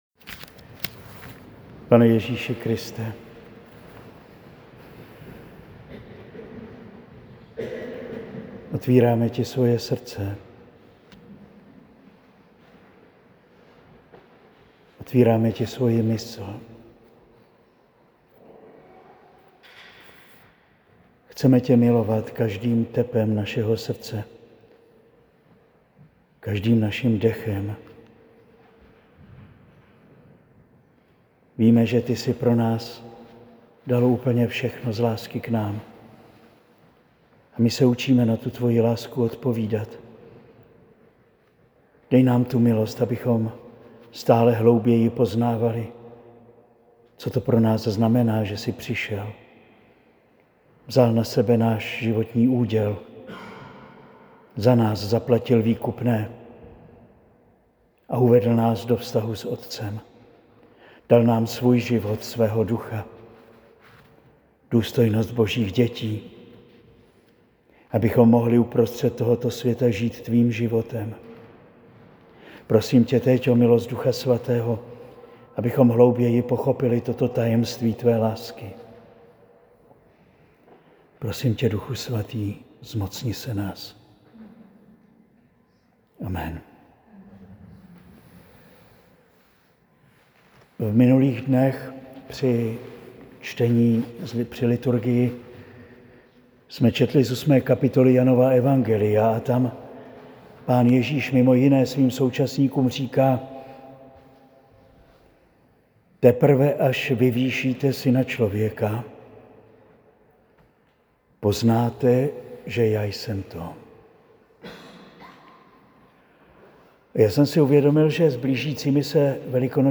Promluva zazněla dne 9. 4. 2025 na večeru chval u Panny Marie Sněžné v Praze.